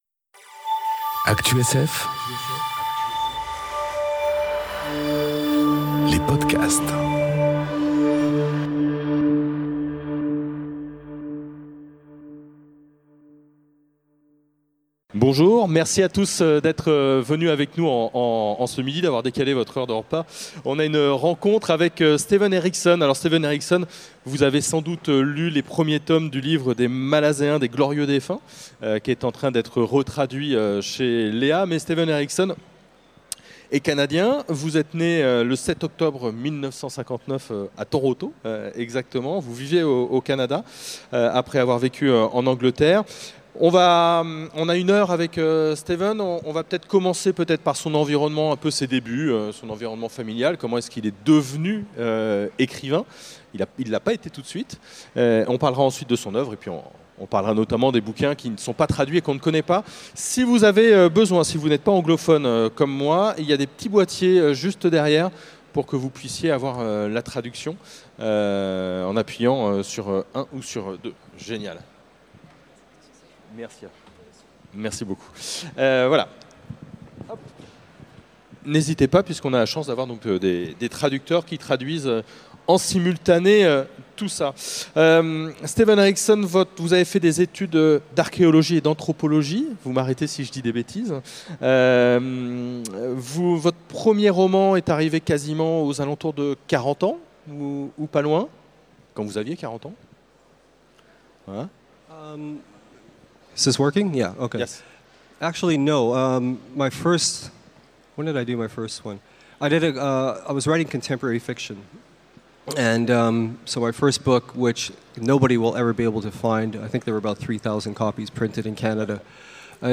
Rencontre avec Steven Erikson enregistrée aux Utopiales 2018